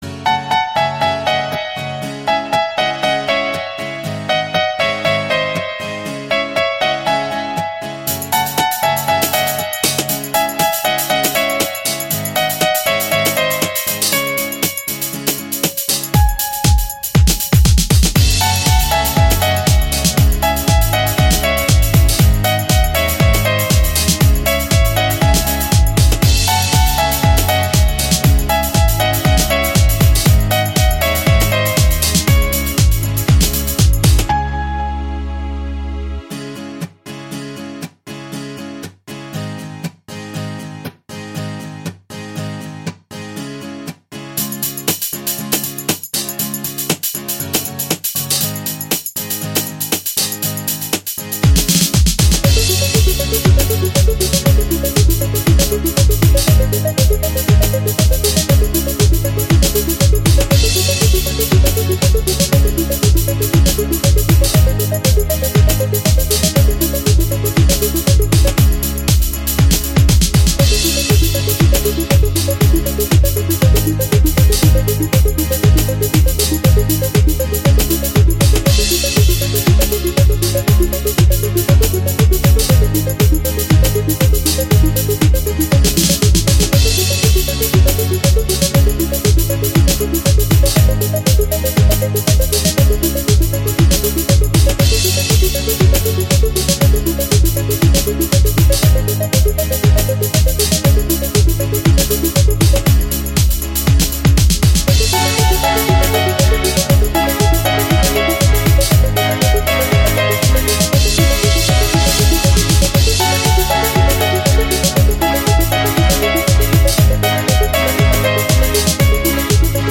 минус от автора